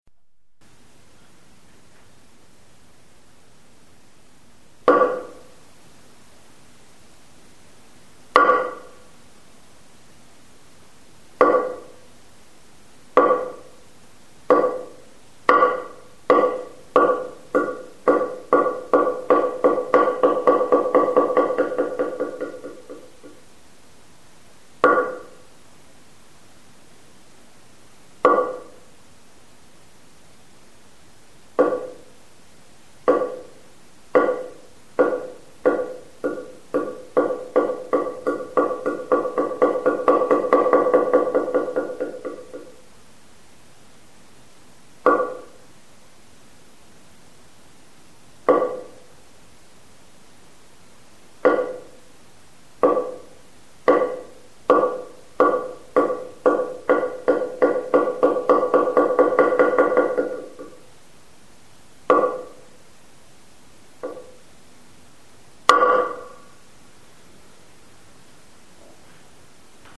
Das Han, vor dem Zendō hängend, wird geschlagen, um zum Beispiel Anfang und Ende von Zazen anzuzeigen.